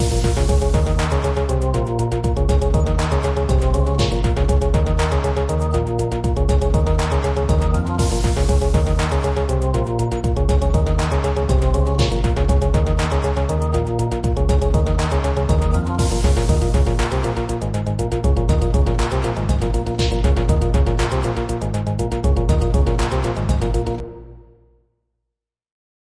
气氛 84
描述：氛围轻微乐观的氛围，适合探索，洞穴等。
Tag: 循环 气氛 音乐 游戏 悲伤 孤独 气氛 节奏